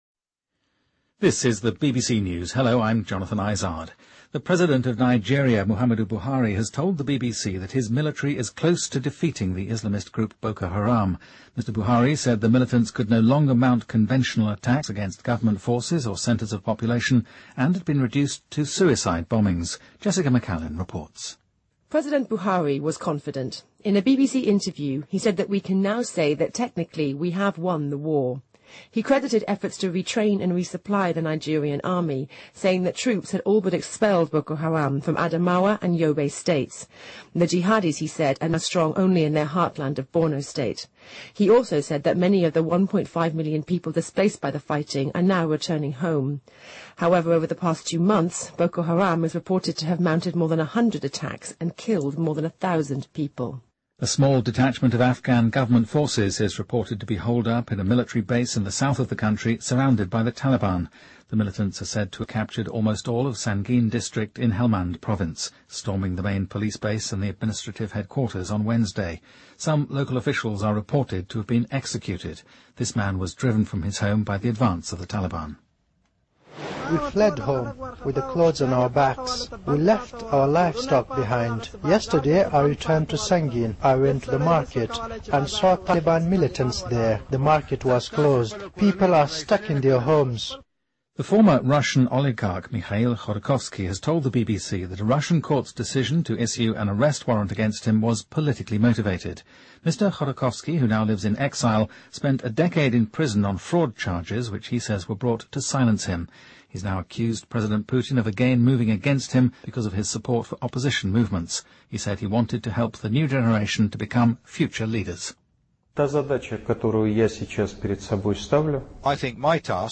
BBC news,俄罗斯前寡头指控普京针对自己
日期:2015-12-25来源:BBC新闻听力 编辑:给力英语BBC频道